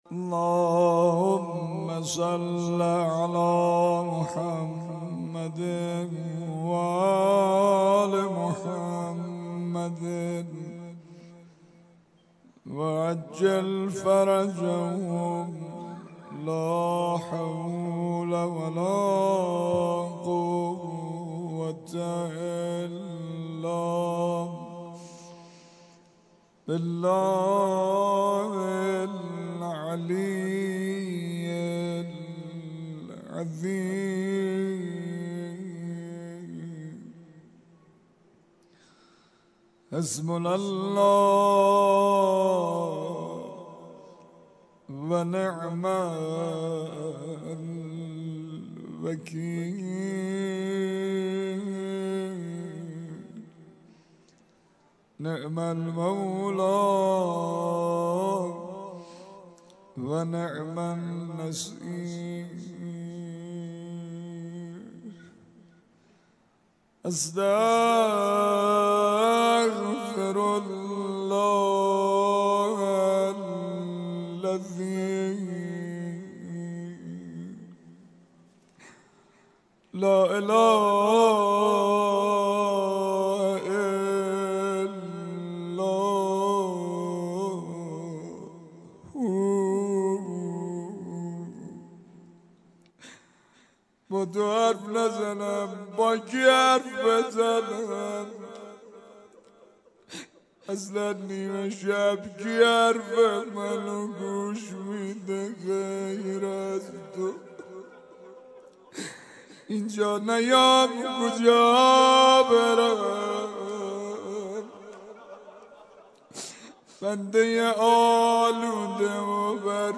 روضه سنگین